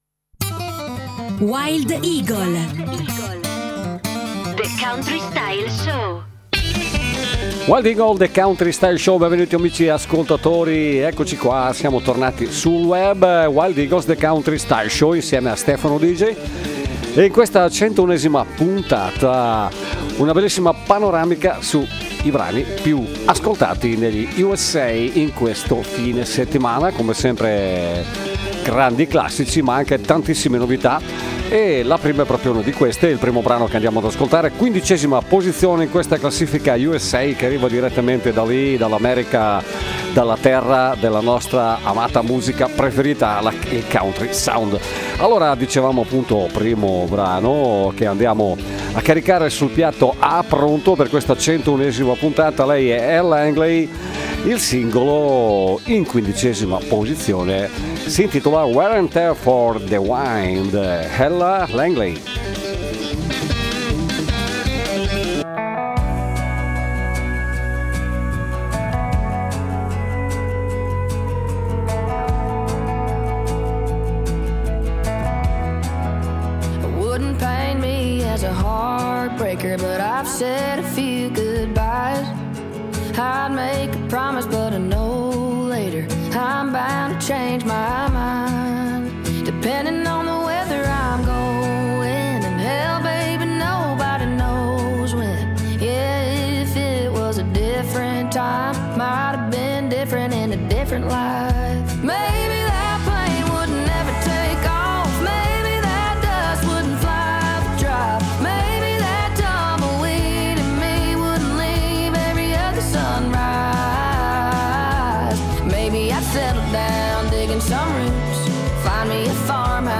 the-holler-country.mp3